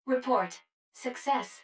153_Report_Success.wav